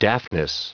Prononciation du mot daftness en anglais (fichier audio)
Prononciation du mot : daftness